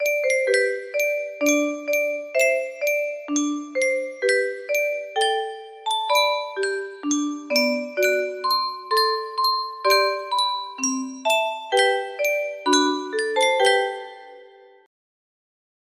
Clone of Yunsheng Tune Music Box - The Yellow Rose of Texas music box melody